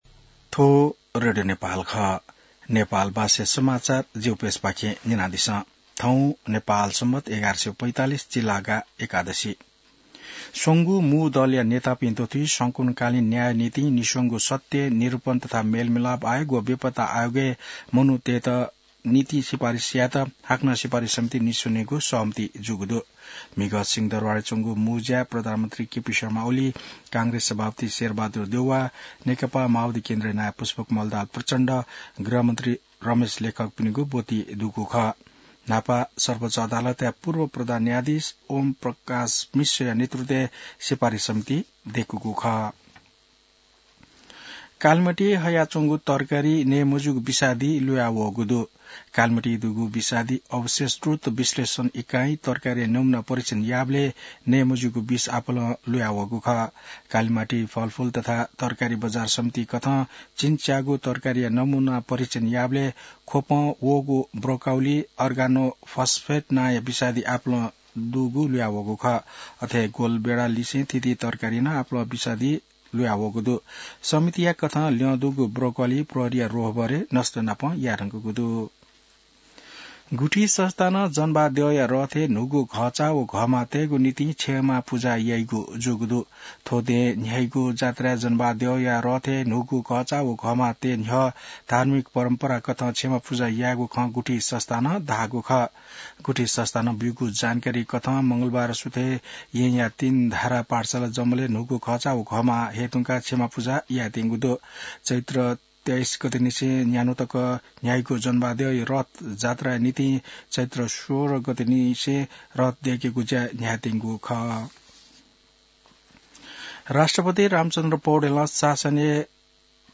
नेपाल भाषामा समाचार : १२ चैत , २०८१